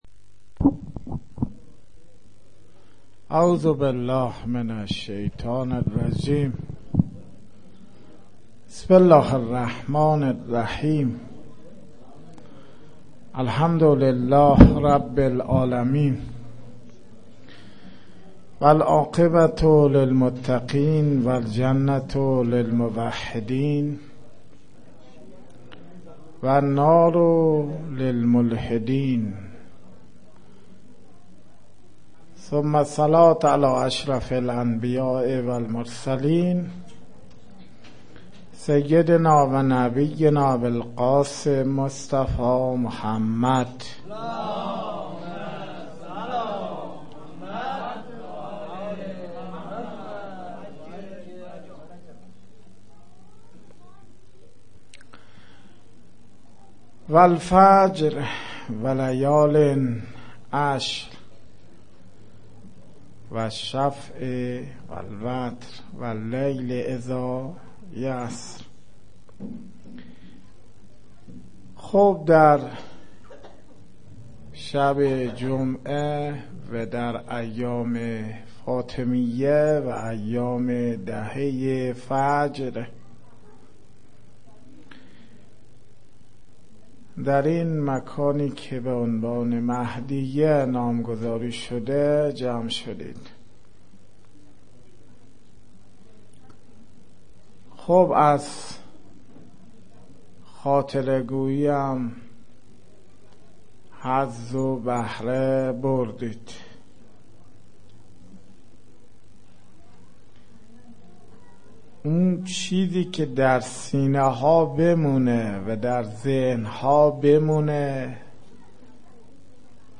مراسم هفتگی